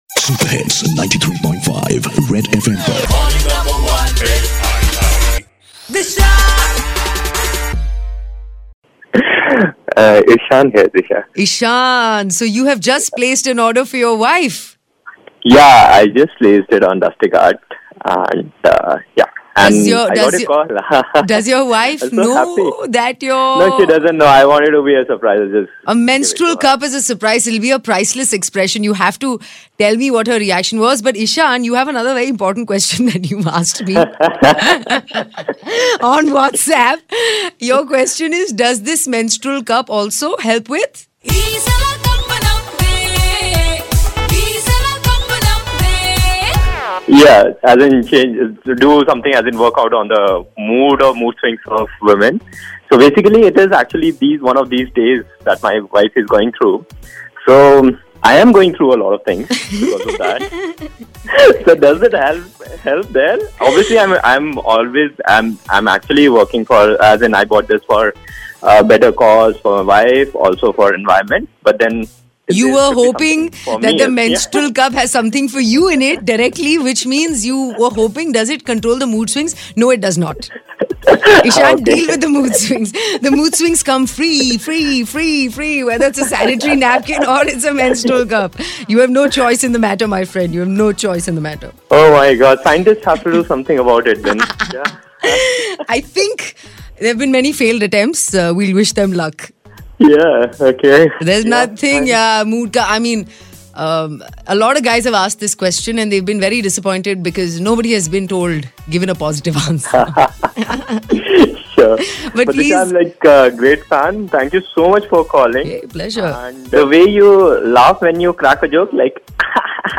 A caller just placed order for a menstrual cup to gift his wife